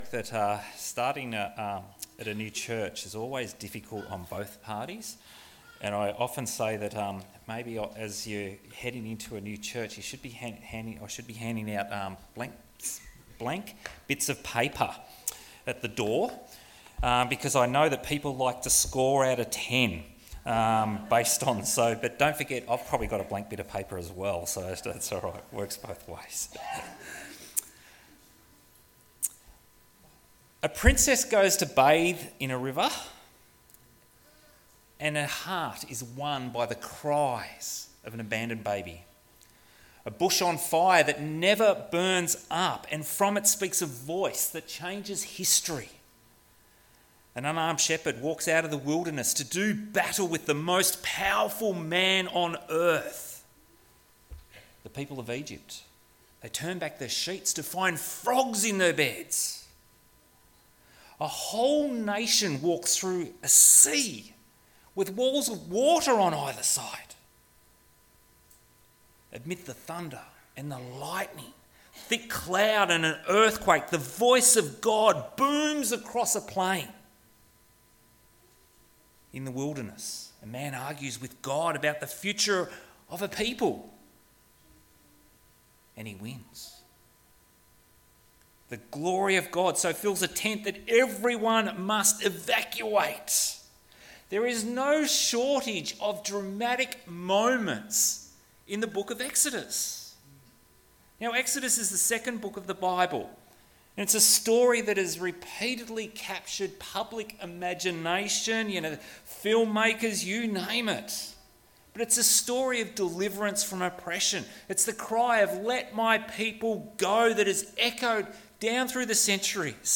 2026 • 27.12 MB Listen to Sermon Download this Sermon Download this Sermon To download this sermon